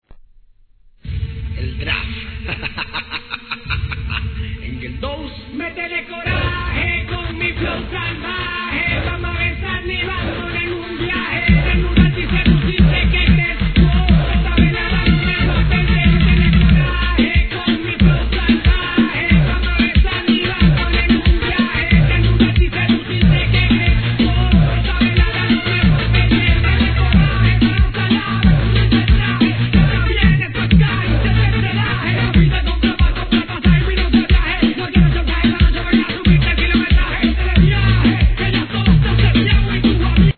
■REGGAETON